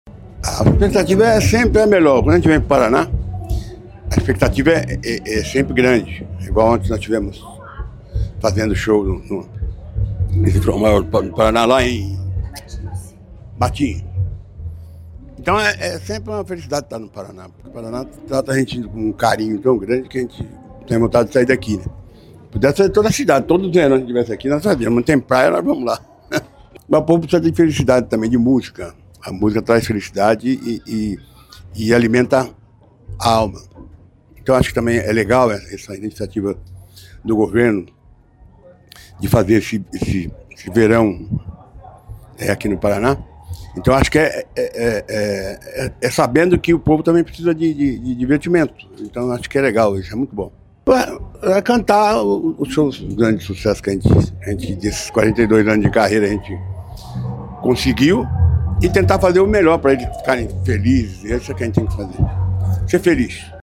Sonora do vocalista da banda Raça Negra, Luiz Carlos, sobre o show deste sábado, em Pontal do Paraná, pelo Verão Maior Paraná